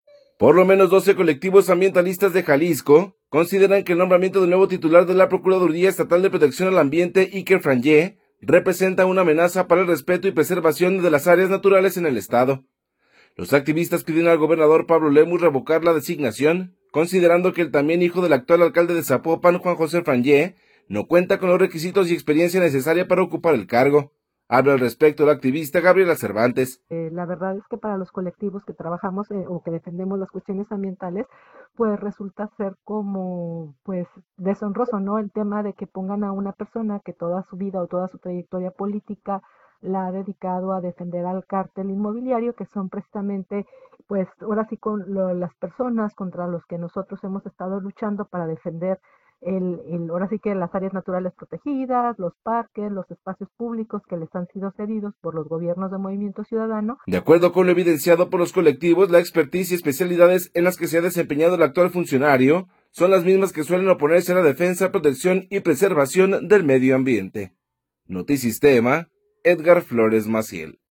Habla al respecto la activista